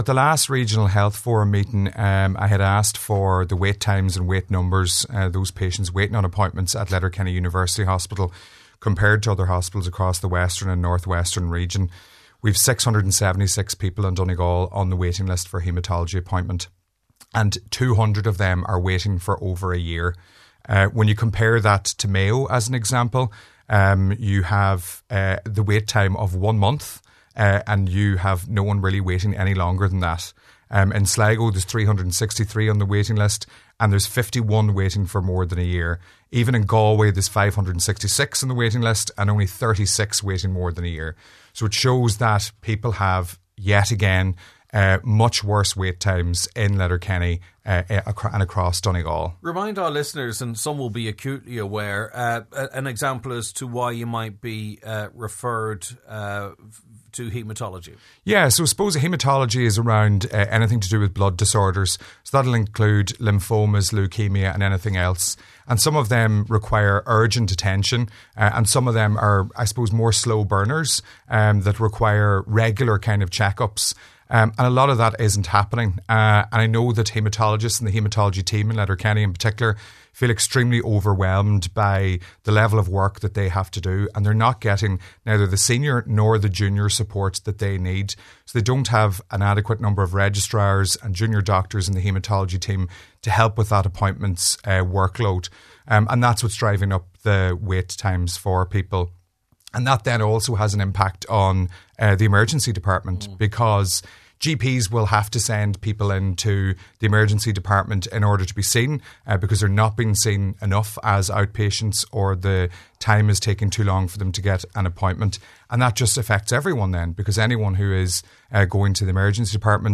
and on thjis morning’s Nine til Noon Show